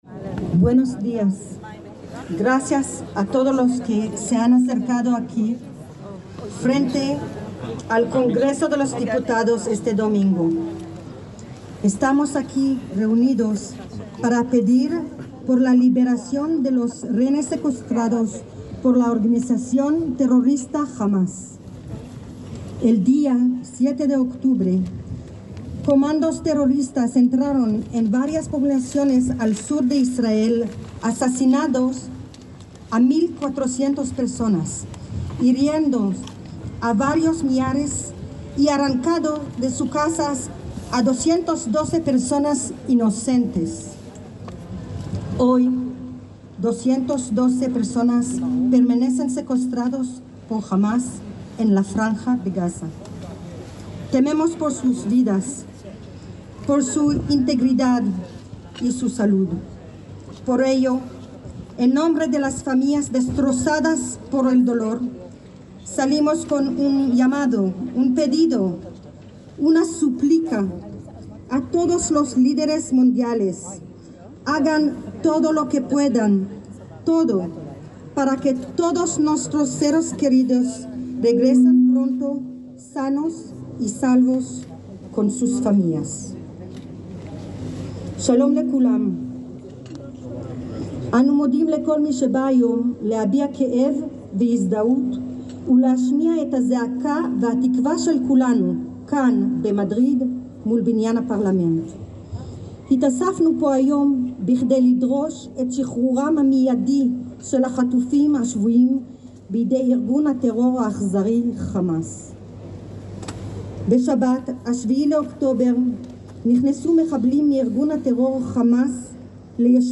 Manifestación Por la liberación de los rehenes secuestrados por la organización terrorista Hamás (frente al Congreso de los Diputados, Madrid, 22/10/2023)